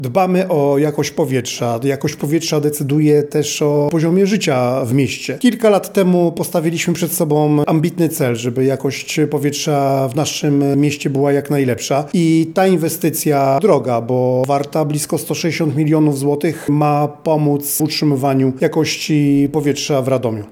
W obu ciepłowniach zostały zamontowane nowe instalacje oczyszczania spalin, dzięki którym znacząco zmniejszy się emisja gazów i pyłów do atmosfery. To bardzo ważna inwestycja dla naszego miasta, mówi prezydent Radosław Witkowski: